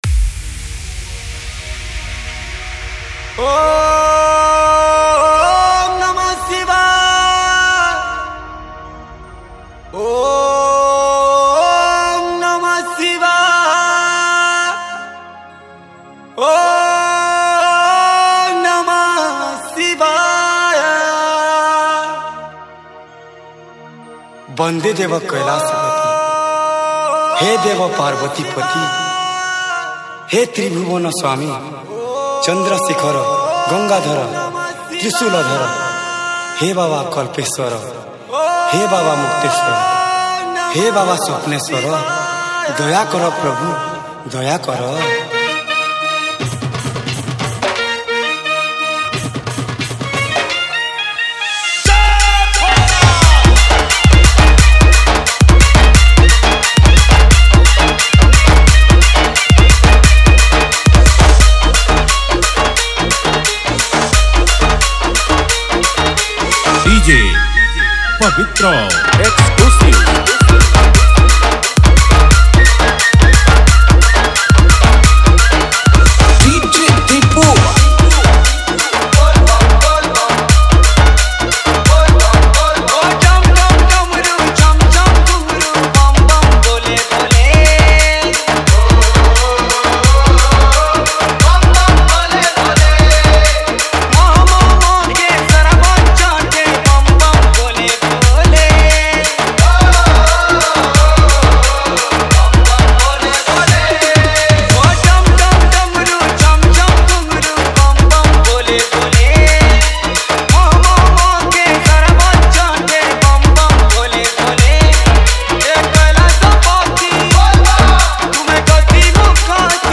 Bolbum Special Dj Song Songs Download